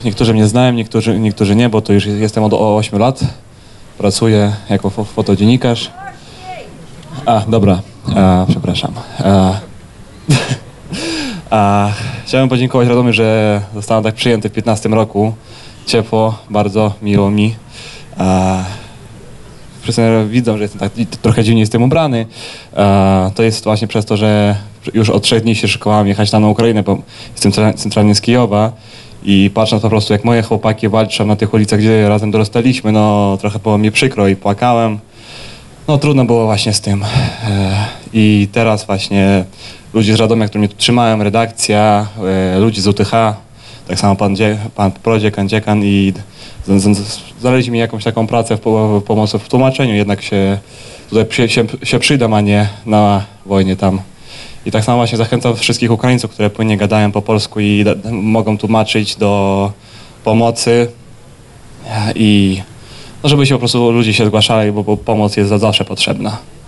Radomianie dla Demokracji i radomska Platforma Obywatelska zorganizowali Wiec Solidarności z Ukrainą.